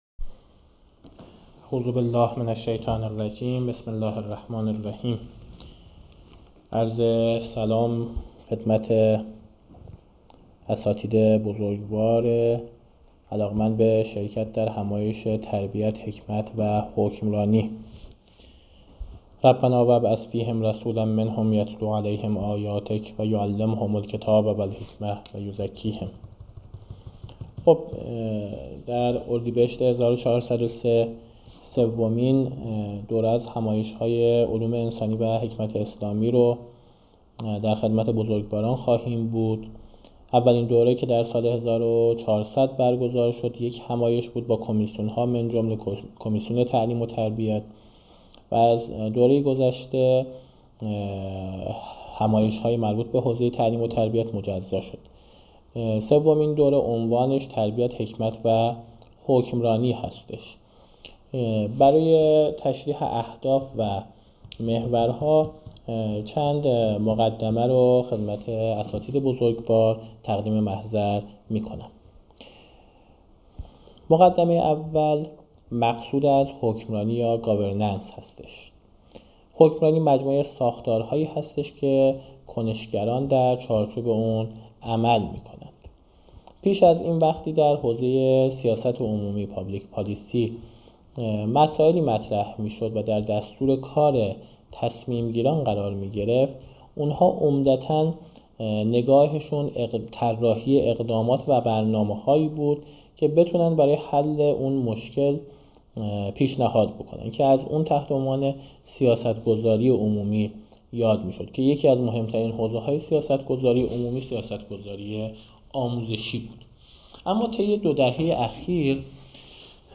اولین پیش همایش تبیین اهداف همایش ملی تربیت حکمت حکمرانی